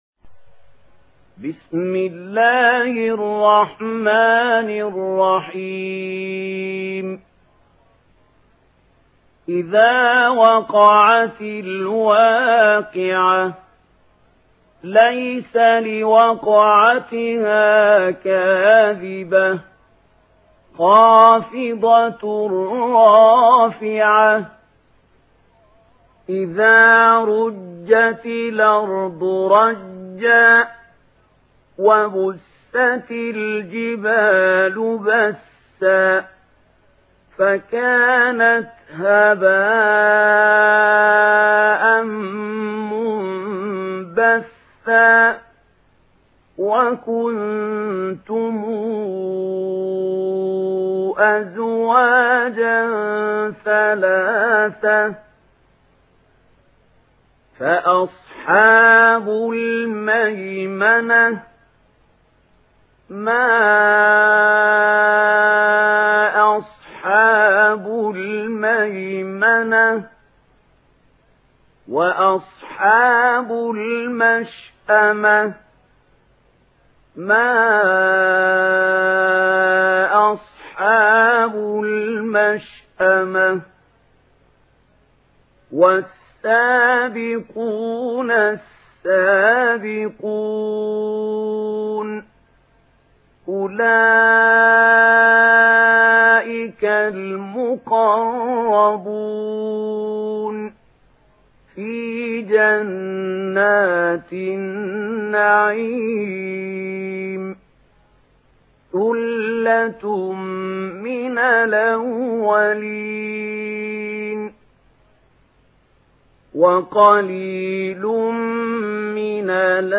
Surah আল-ওয়াক্বি‘আহ MP3 in the Voice of Mahmoud Khalil Al-Hussary in Warsh Narration
Murattal Warsh An Nafi